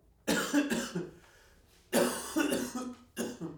cough2.ogg